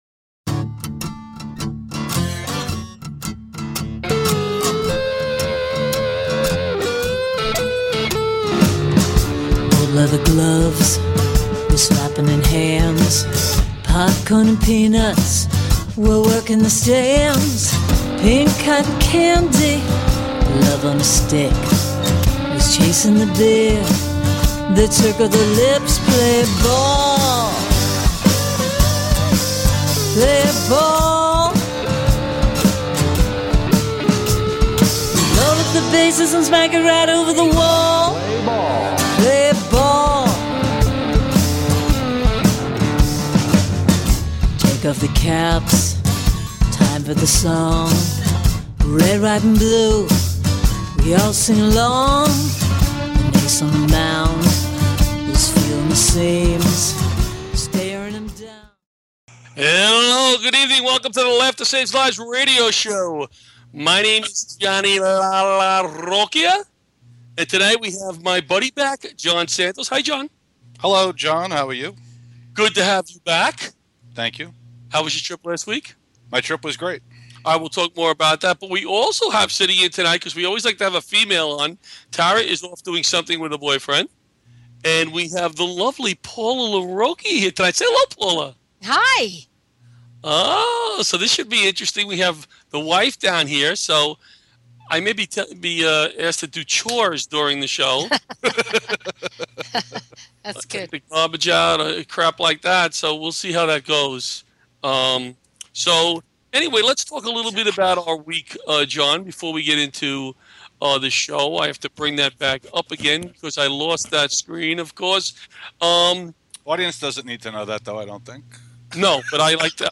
Talk Show Episode
a plethera of Comedians